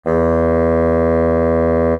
bassoon_E2_ff.mp3